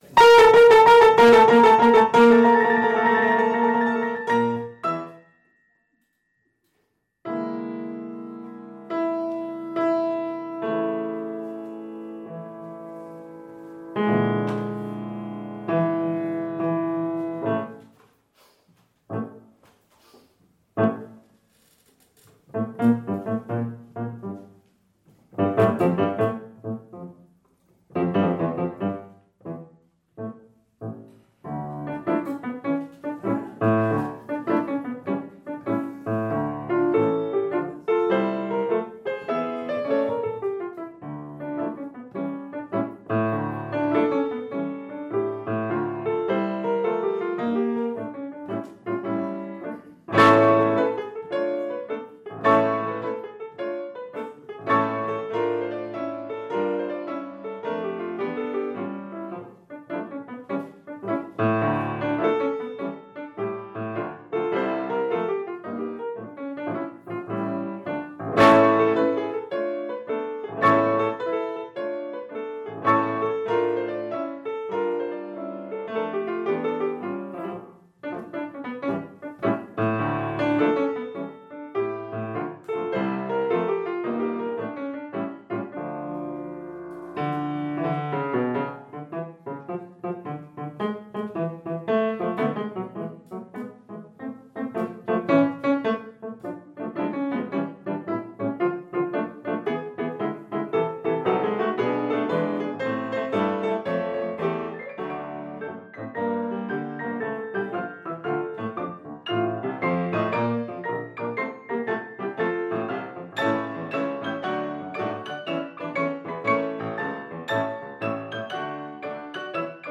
Dopo la lunga esperienza legata all’ensemble, hanno deciso di cimentarsi nel repertorio più tradizionale del pianoforte a 4 mani, senza tralasciare però la forma della trascrizione dall’orchestra e brani del repertorio contemporaneo. Il programma proposto è un percorso esplorativo che, partendo dal classicismo mozartiano si spingerà fino a quello che viene definito minimalismo.